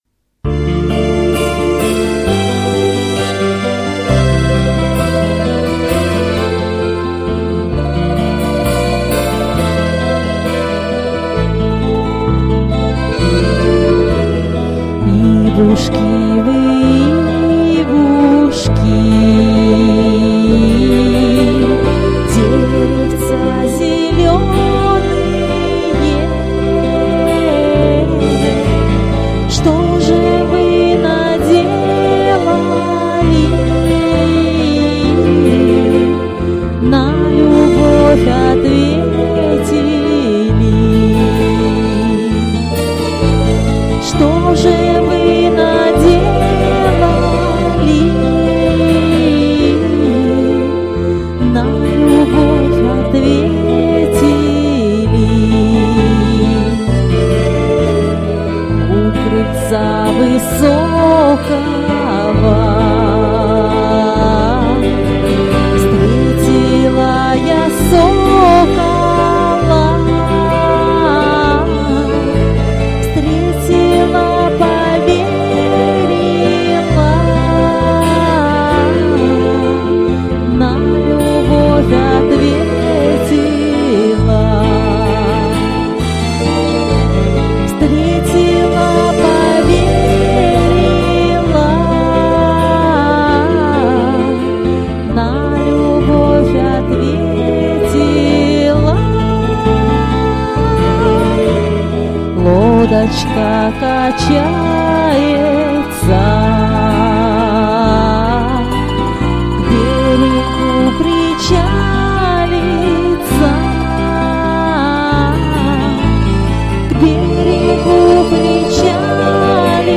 «Русские-народные песни»